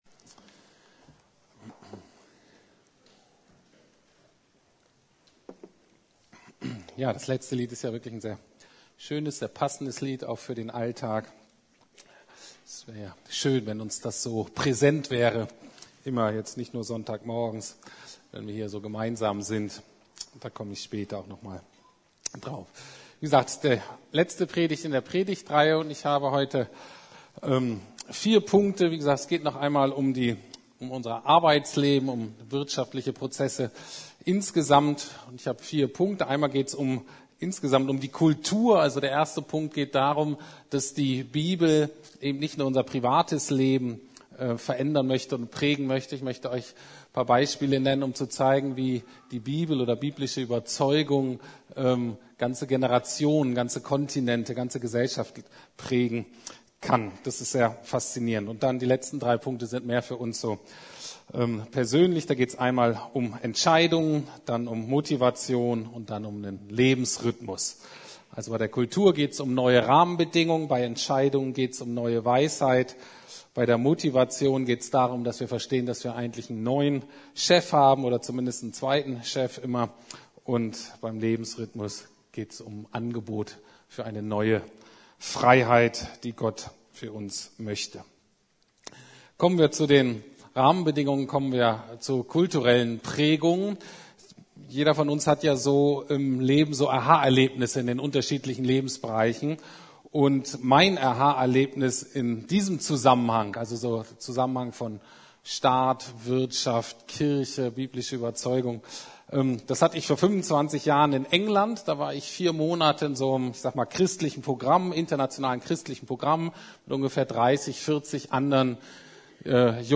Wie Gott die Welt regiert: Die Wirtschaft Teil III ~ Predigten der LUKAS GEMEINDE Podcast